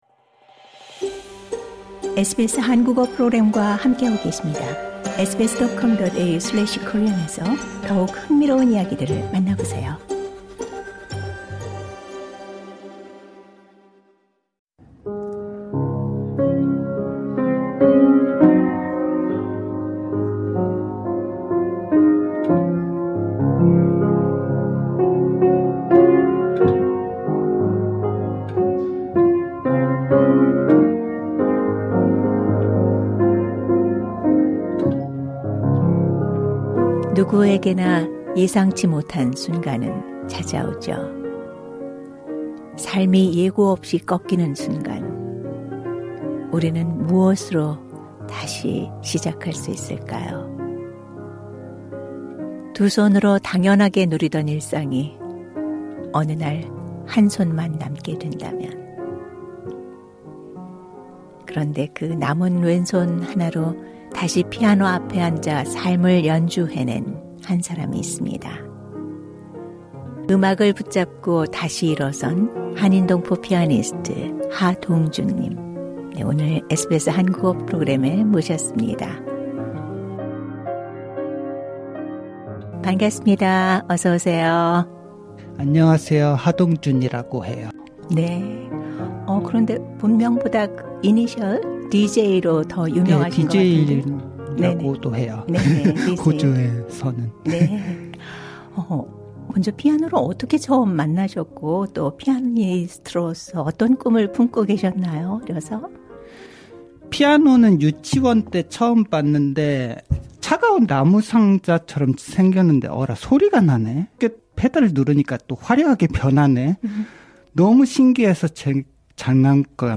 한 해동안 가장 많은 관심을 받았던 인터뷰 기사 TOP 5를 선정해 다시 돌아봅니다.